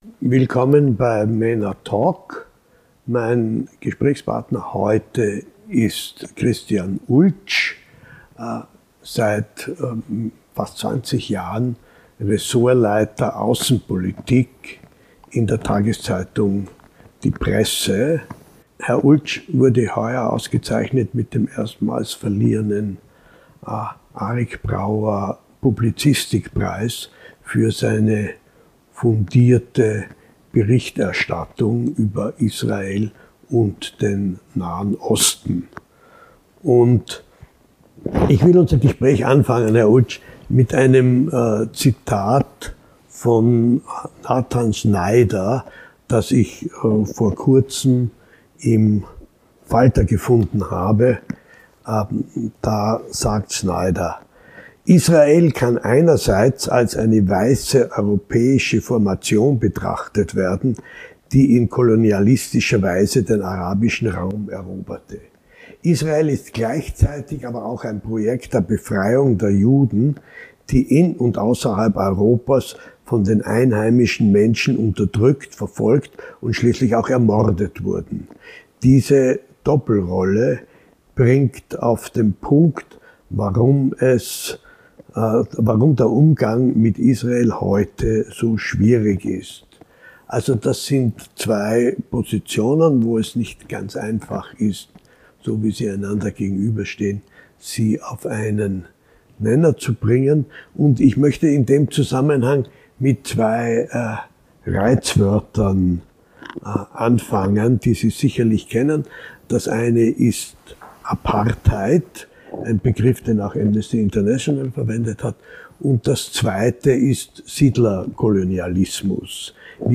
Ein Gespräch über Israel, Israelkritik und die Vorwürfe von Apartheid und Siedlerkolonialismus.